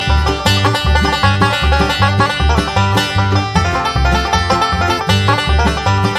Play That Banjo!